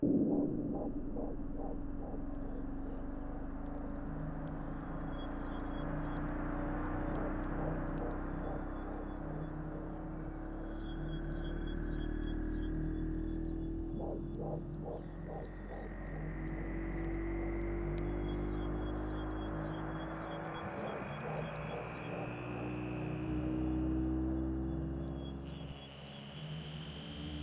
描述：循环的抽象背景音乐
标签： 环境 背景 音乐
声道立体声